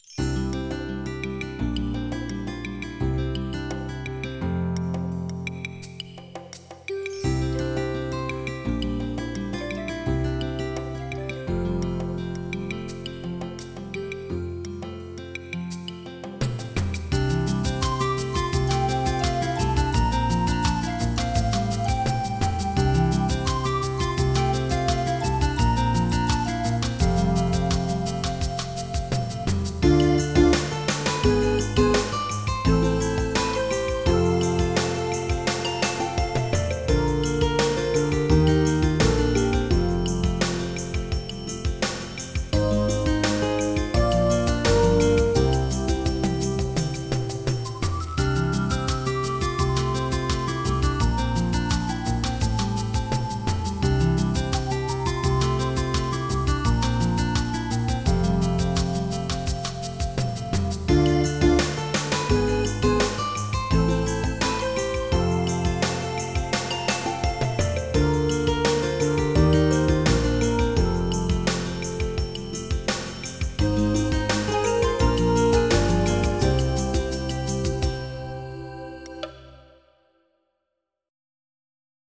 Jazz
MIDI Music File
General MIDI